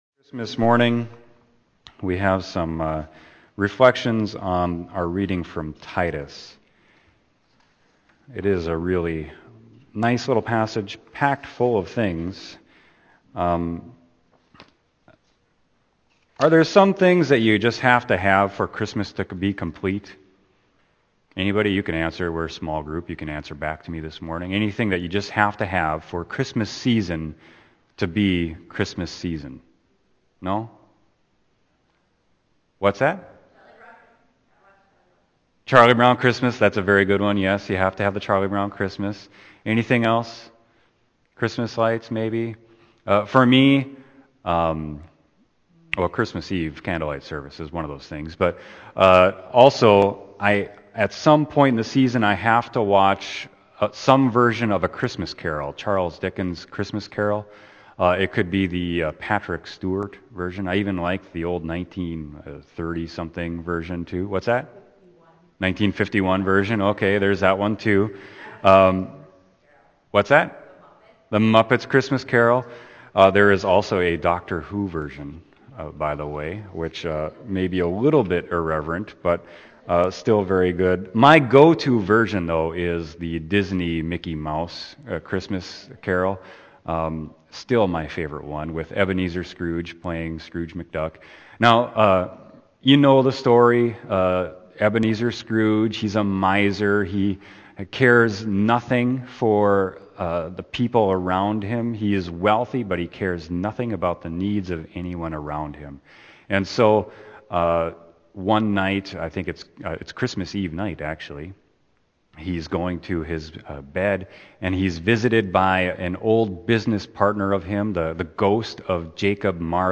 Sermon: Christmas Day 2015